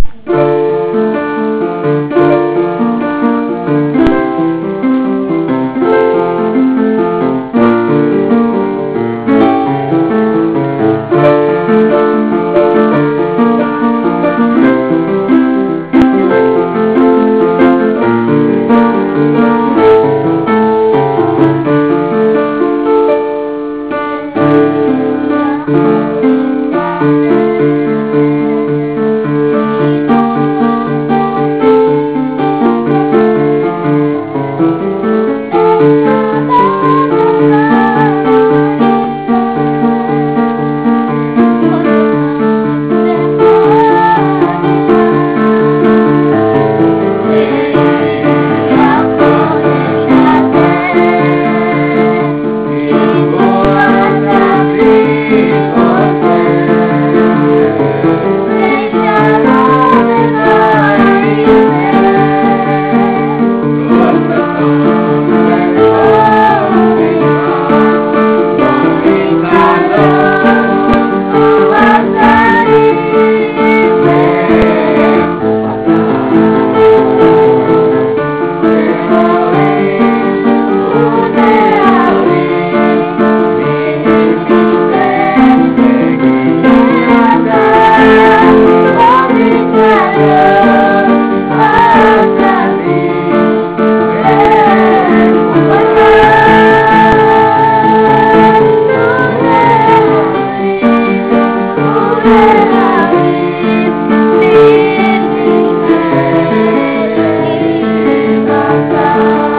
אחותי הלחינה את השיר הזה לכבוד יום חתונתה אני על הפסנתר ואחי ואבי היקר שרים..